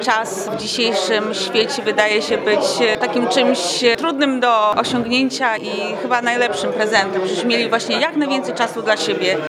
To już piękna świąteczna tradycja – wigilia w Radiu 5 Ełk.